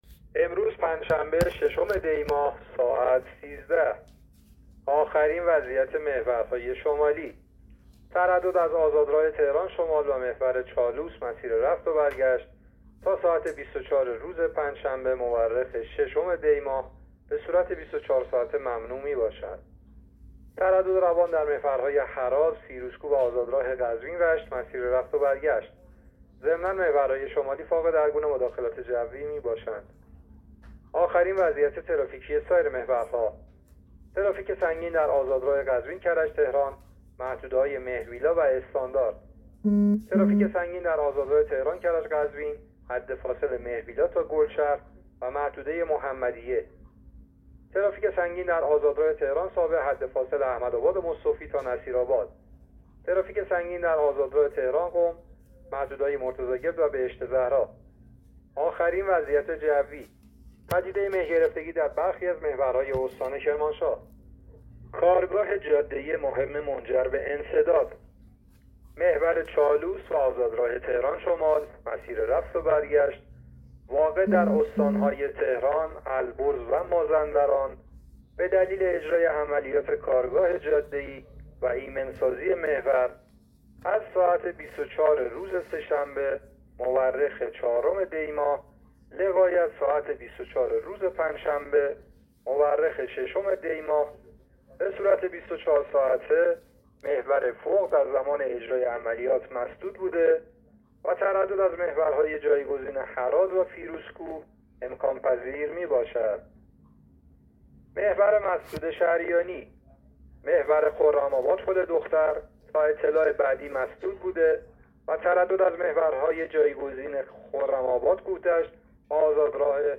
گزارش رادیو اینترنتی از آخرین وضعیت ترافیکی جاده‌ها تا ساعت ۱۵ ششم دی؛